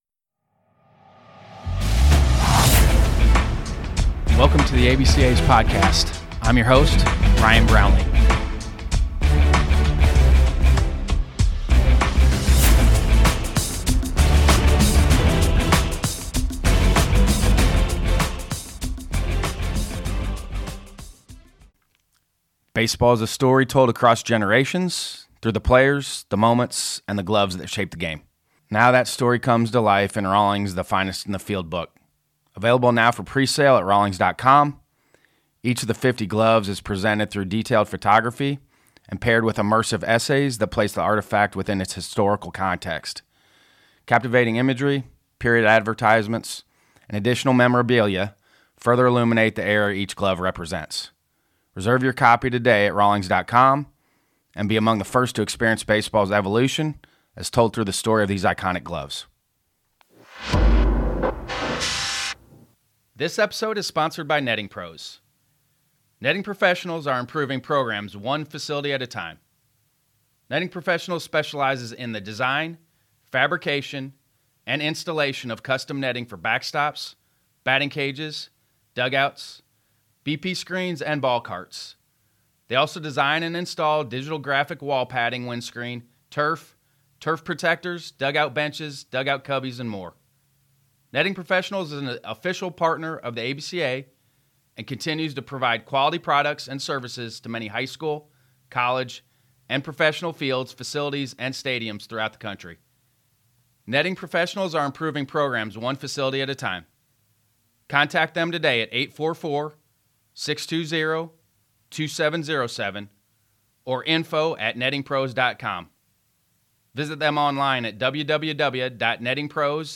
We made our annual trek over to Cary, NC for the USA Baseball NHSI Tournament to sit down with some of our ABCA member coaches.